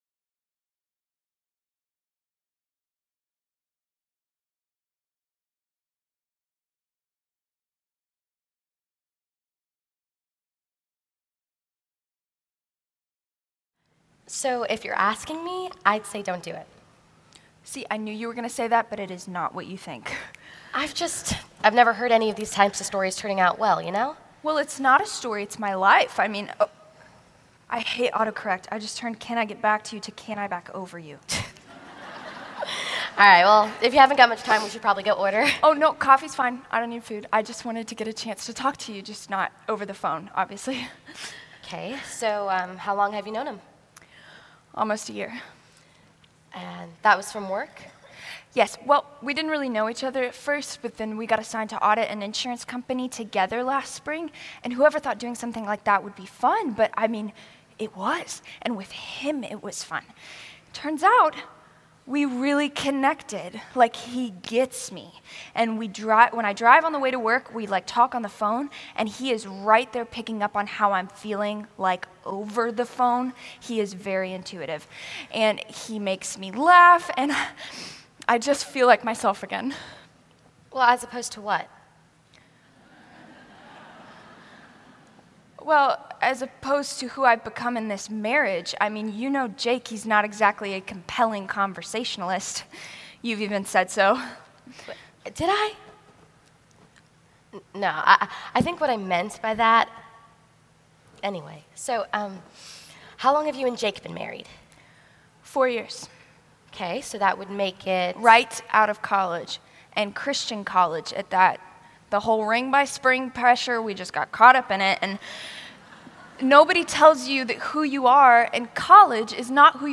R17 - Drama 09 - Purity.MP3